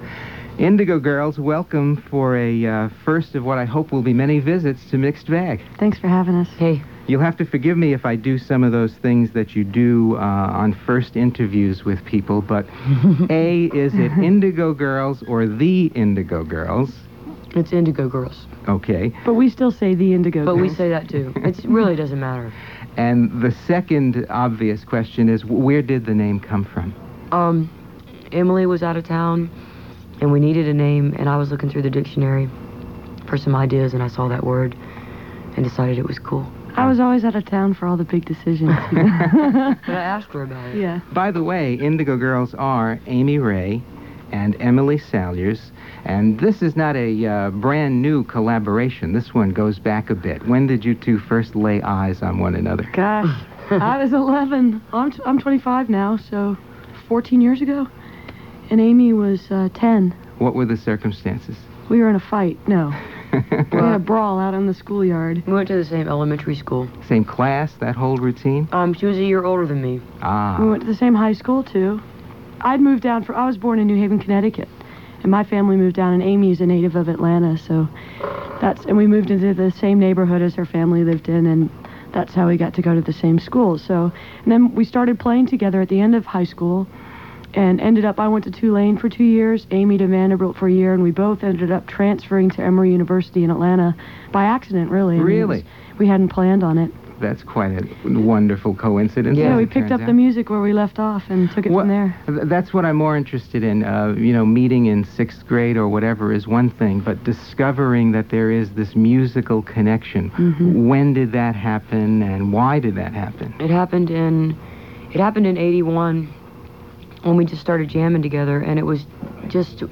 03. interview (6:43)